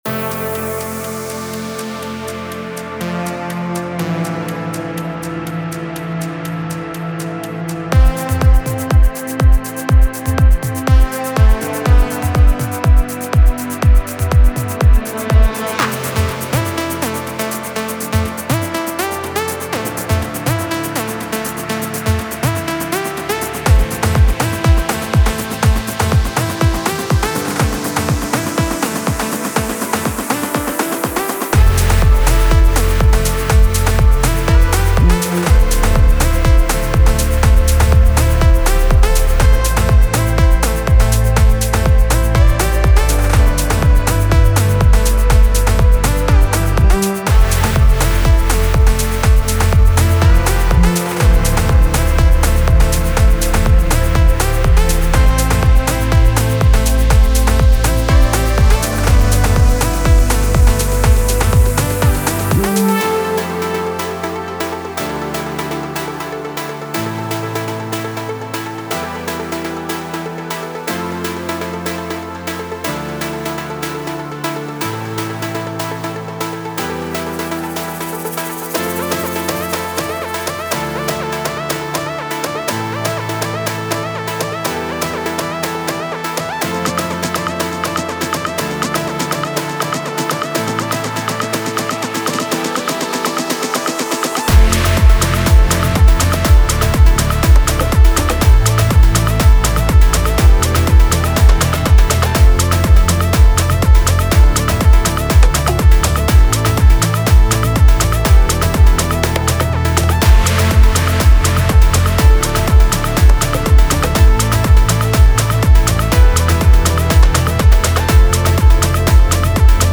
Genre:Afro House
15 Percussion Loops
7 Piano Loops
18 Synth Loop